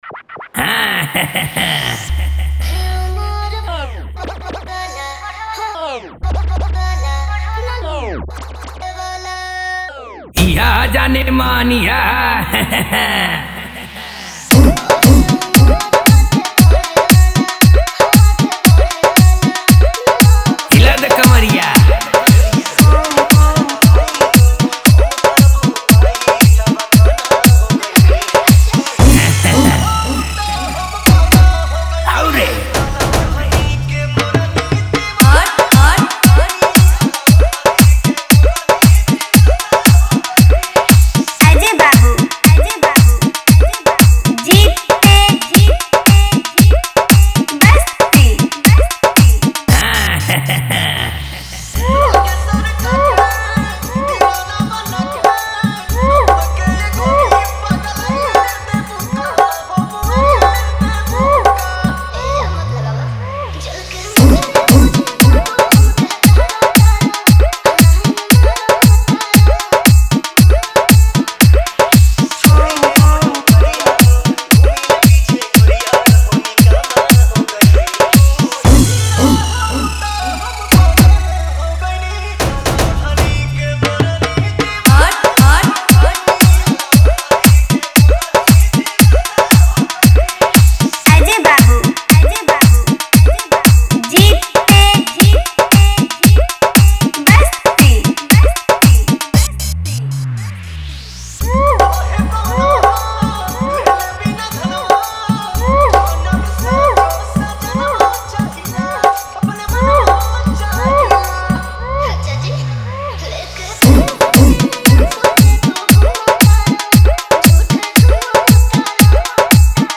Category : Bhojpuri Wala Dj Remix